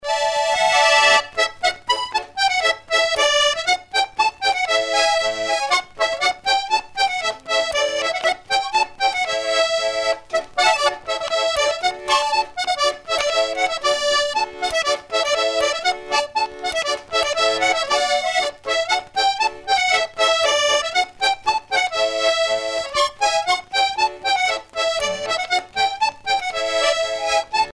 danse : branle
danse : polka
Pièce musicale éditée